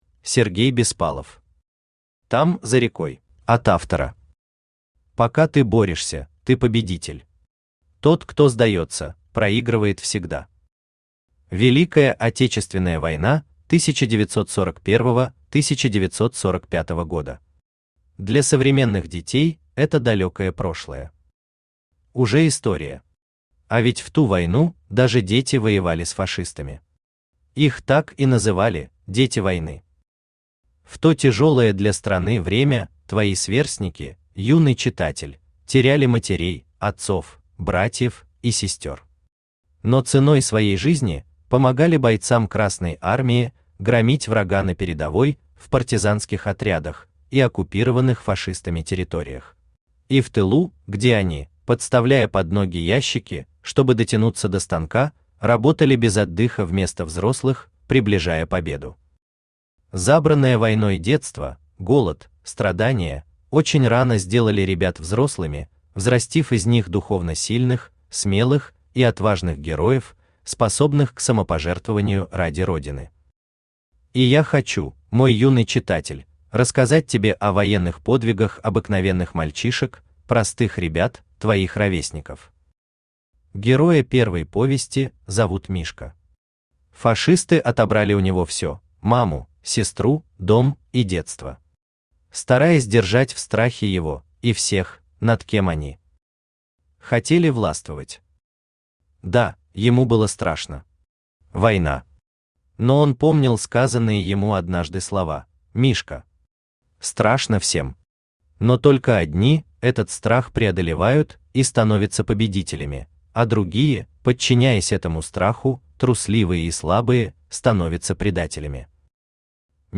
Аудиокнига Там, за рекой…
Автор Сергей Александрович Беспалов Читает аудиокнигу Авточтец ЛитРес.